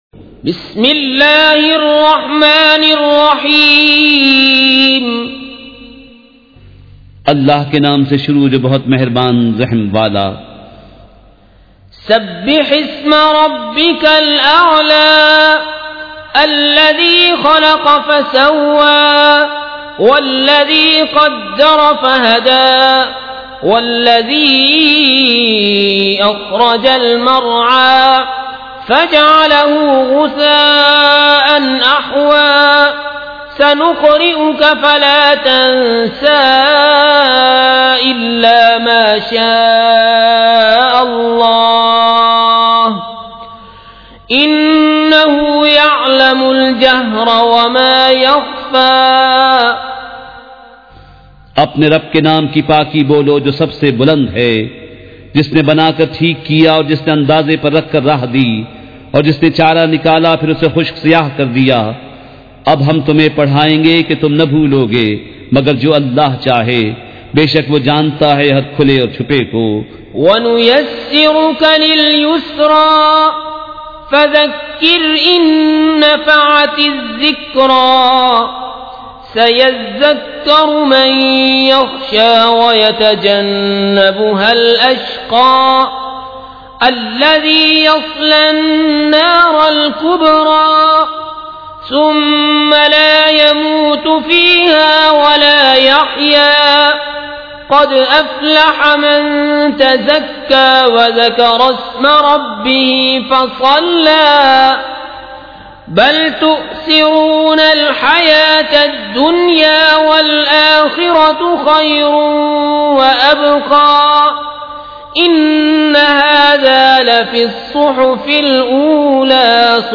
سورۃ الاعلیٰ مع ترجمہ کنزالایمان ZiaeTaiba Audio میڈیا کی معلومات نام سورۃ الاعلیٰ مع ترجمہ کنزالایمان موضوع تلاوت آواز دیگر زبان عربی کل نتائج 1864 قسم آڈیو ڈاؤن لوڈ MP 3 ڈاؤن لوڈ MP 4 متعلقہ تجویزوآراء
surah-al-ala-with-urdu-translation.mp3